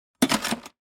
shovelhit.mp3